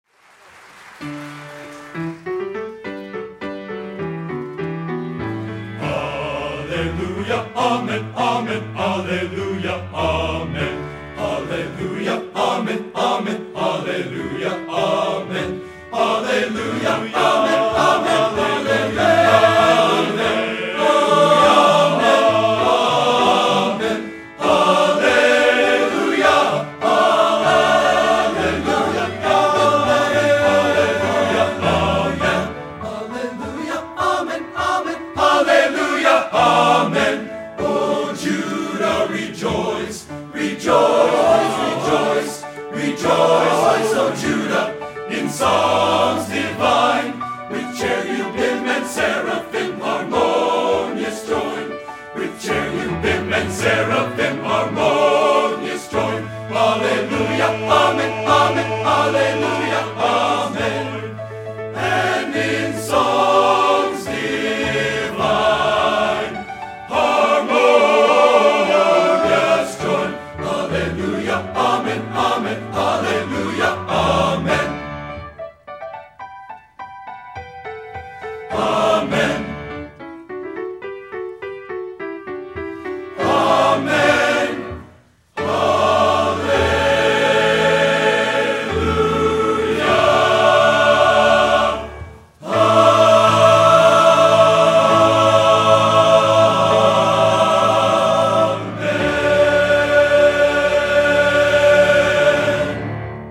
Location: Northrop High School, Fort Wayne, Indiana